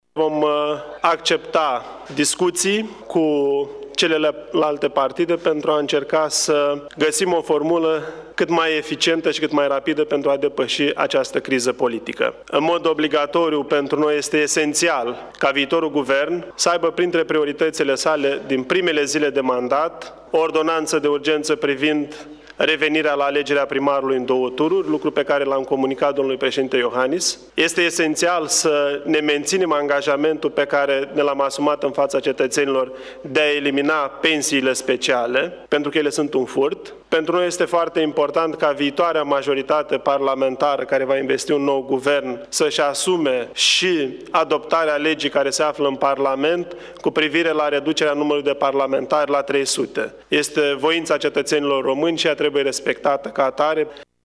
Președintele PMP, Eugen Tomac, a anunțat, la finalul discuțiilor cu șeful statului, că sunt de acord să poarte discuții cu celelalte partide pentru a depăși cât mai rapid criza politică:
stiri-11-oct-declaratii-PMP.mp3